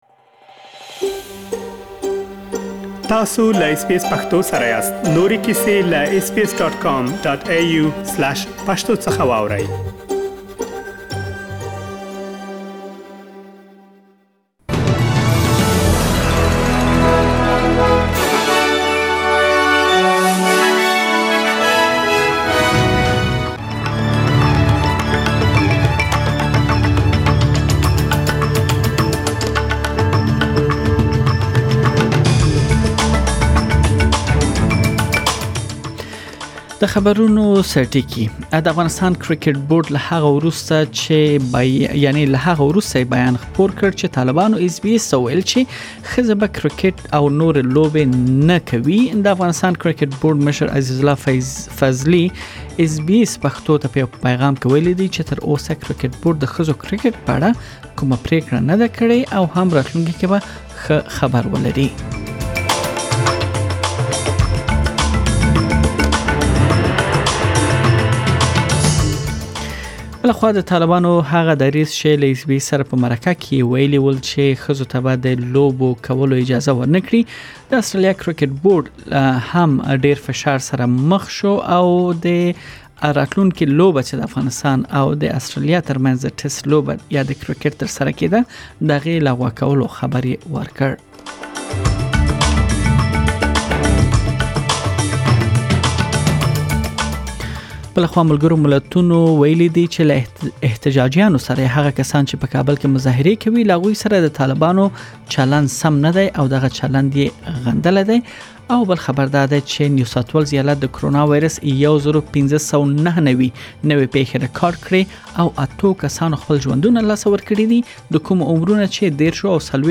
د نن ورځې مهم خبرونه